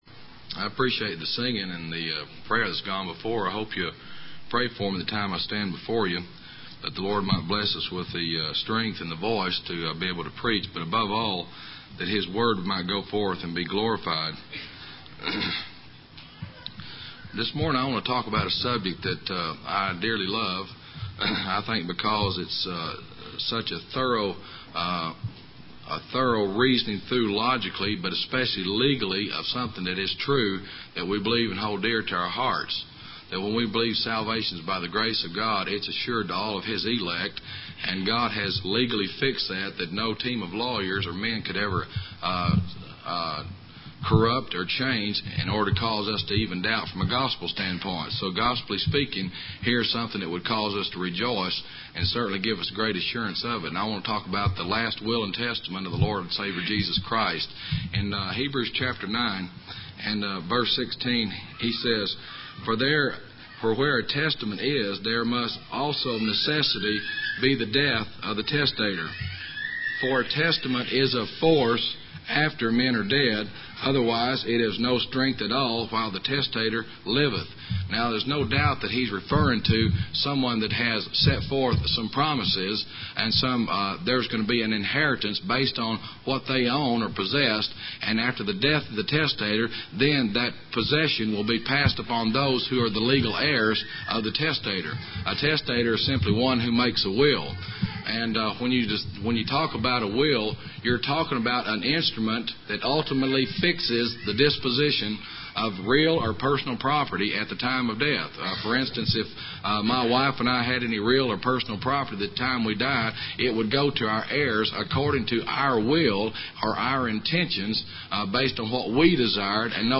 Middleton Creek PBC (MS) %todo_render% « Gift of Exhortation Changes